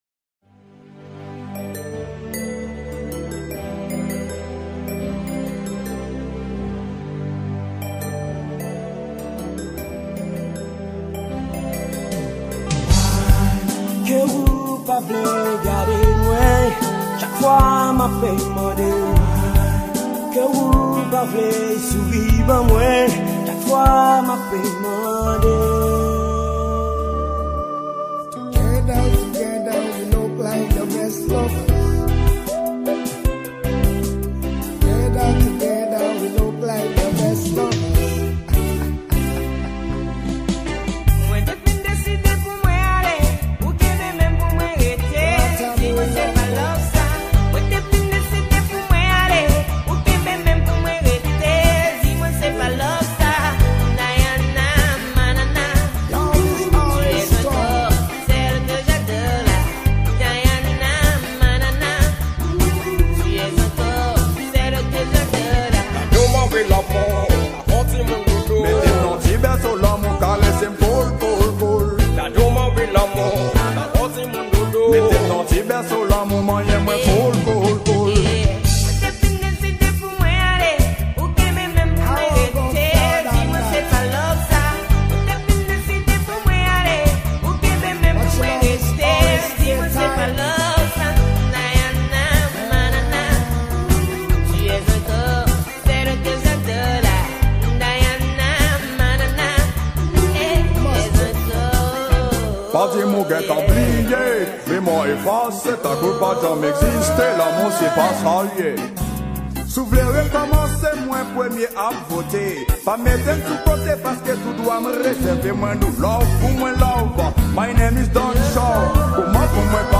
Genre: Ragga Muffin